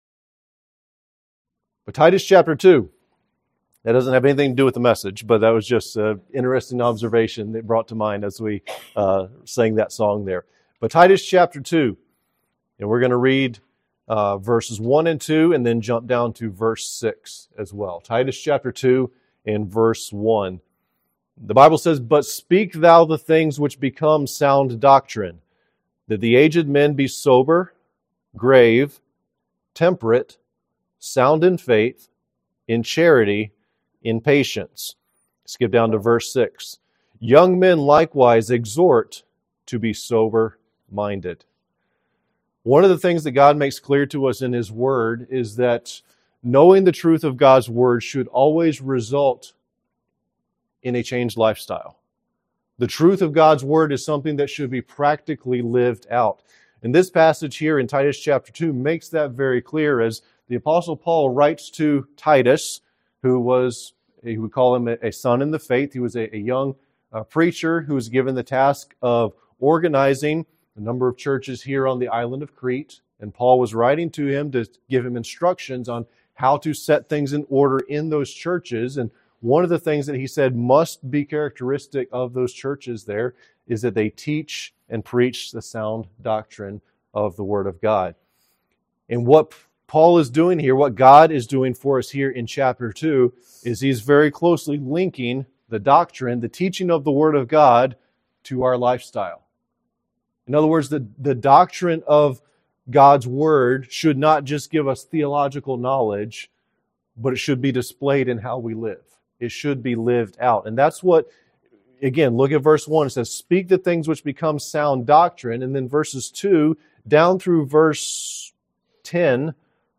What society and culture's expectations are often are directly opposed to what God wants from men. In this Father's Day message, we look at God's expectations for men of all ages from Titus chapter 2.